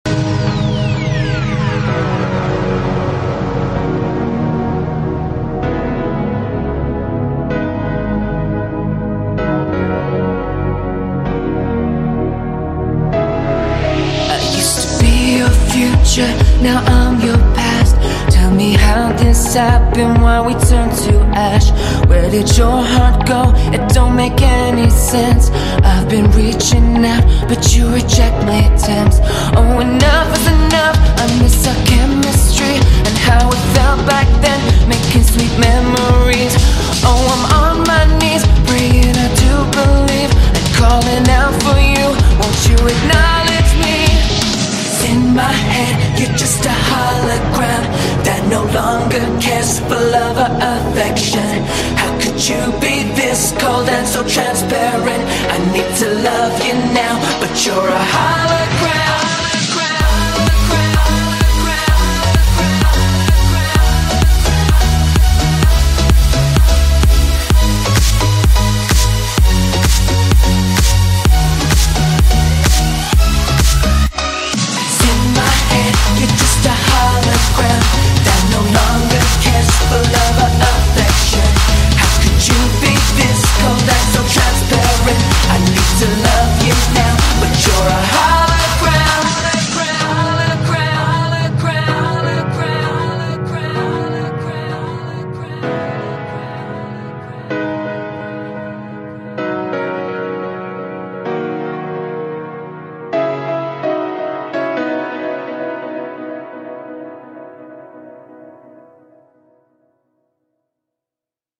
BPM64-128
Audio QualityMusic Cut